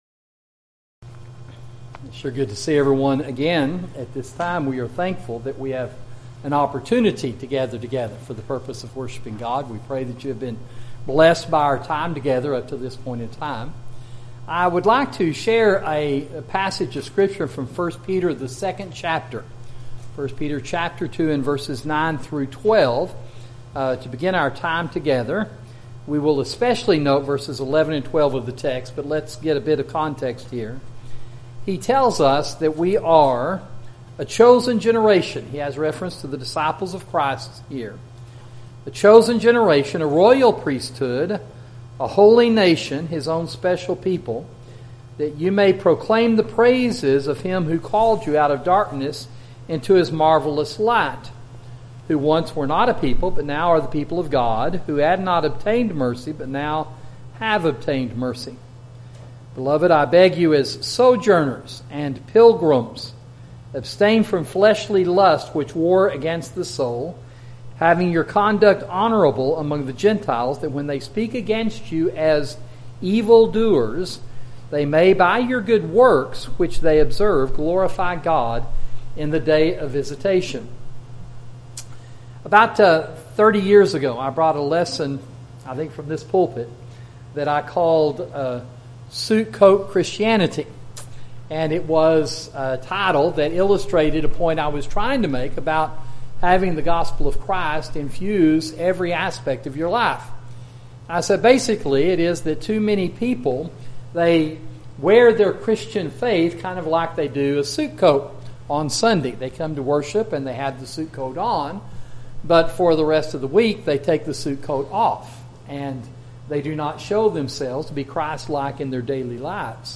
Sermon: The Disciple and the World – Sound Teaching